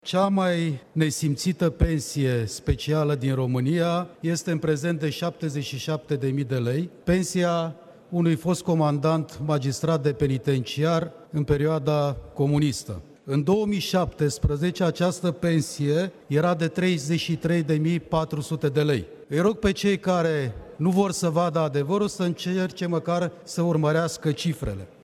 Liderul deputaţilor liberali, Florin Roman a explicat în plen că “favorizarea unor categorii a dus la anomalii”: